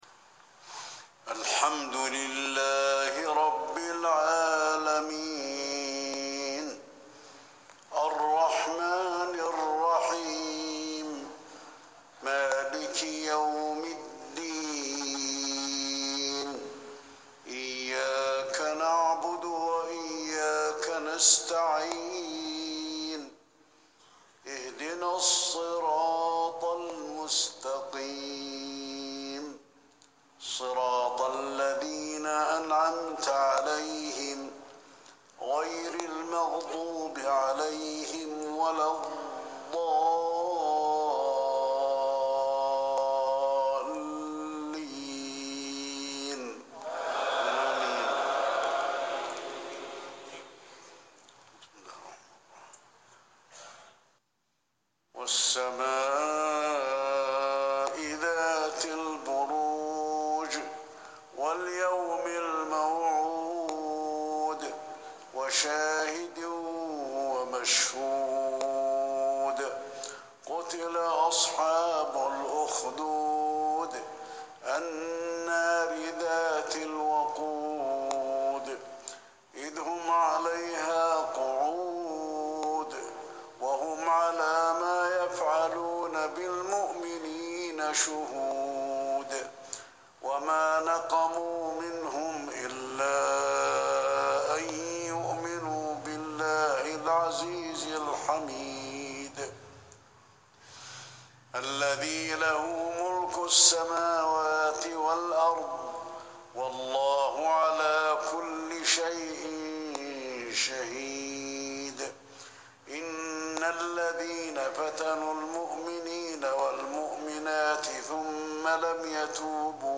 عشاء السبت 2 رجب 1440 هـ سورتي البروج و الطارق | Isha prayer from Surah Al-Boroj and At-Tariq 9-3-2019 > 1440 🕌 > الفروض - تلاوات الحرمين